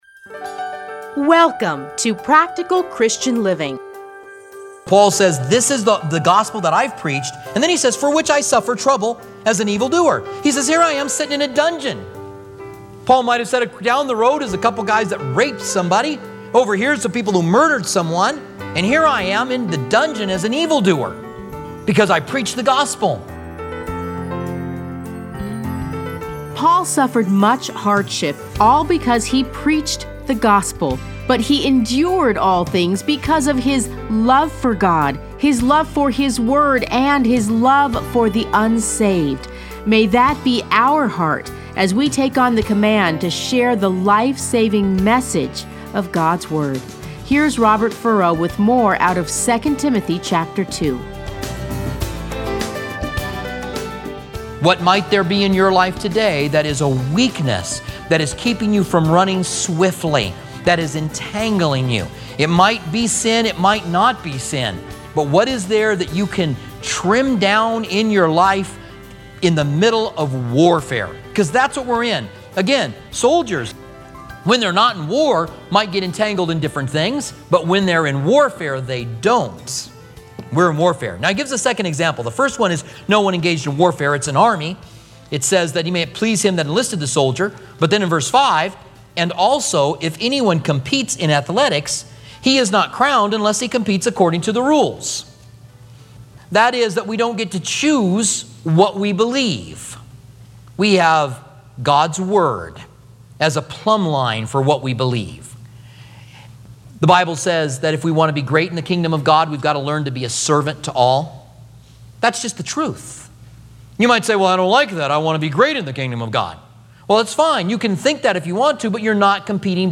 Commentary - 2 Timothy 2 - Part 2